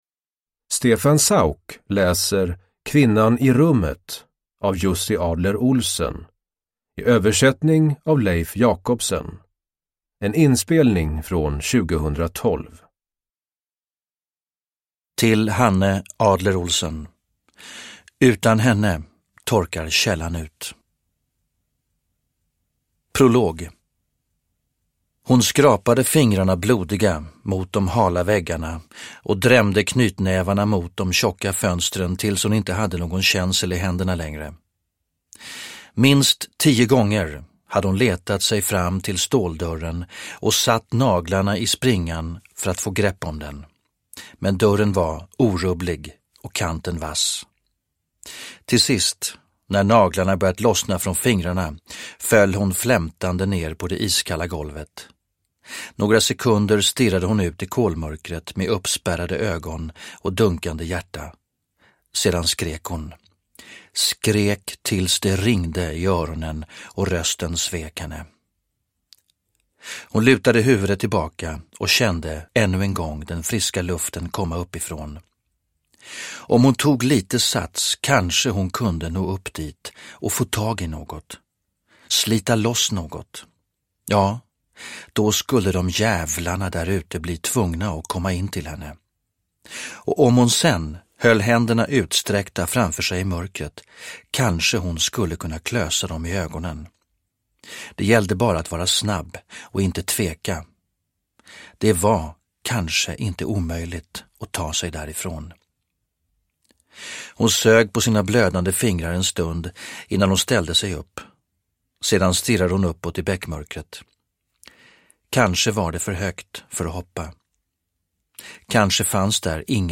Kvinnan i rummet – Ljudbok – Laddas ner
Uppläsare: Stefan Sauk